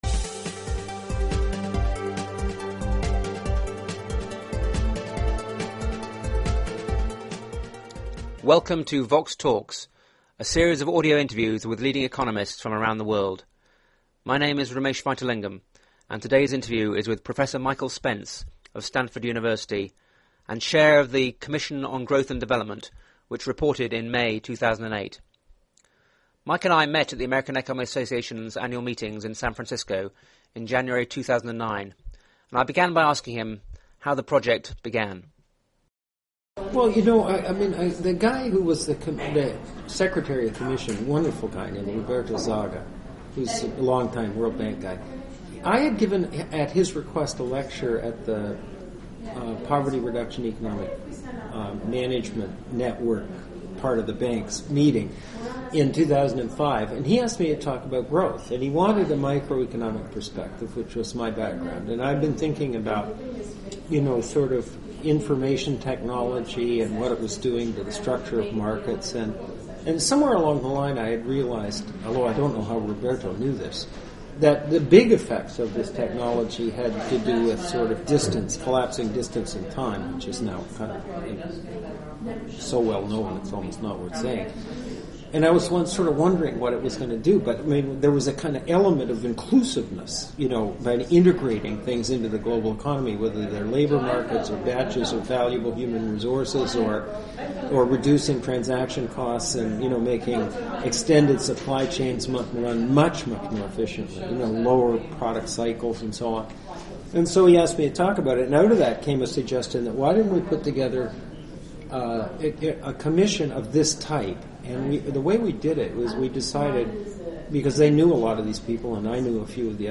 The interview was recorded at the American Economic Association meetings in San Francisco in January 2009.